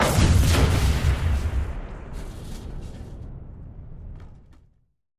Single explosion with glass breaking and light debris.